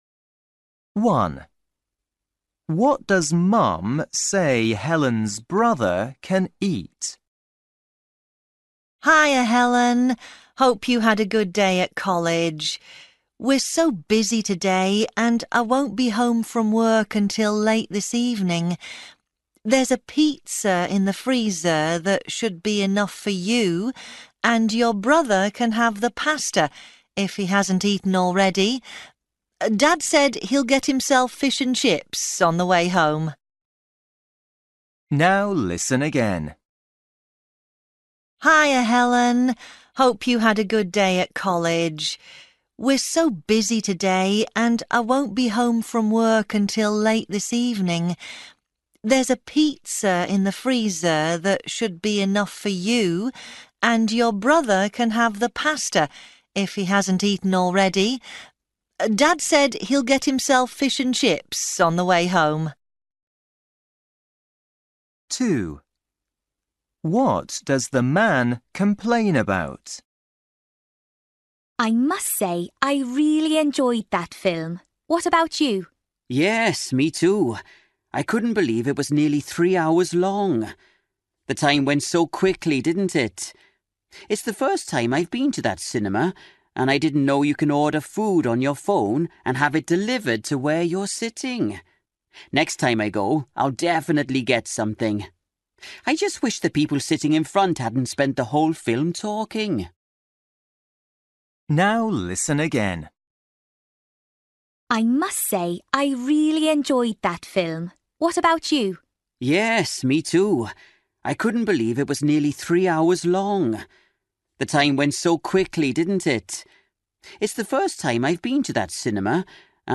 Listening: Everyday Conversations and Activities